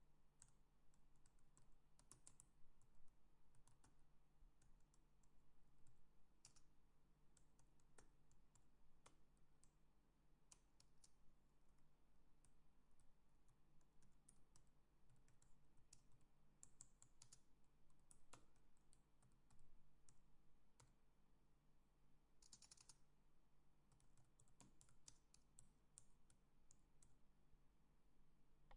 办公室声音 " 键盘打字
描述：在电脑键盘上打字
Tag: 键盘 输入 电脑 打字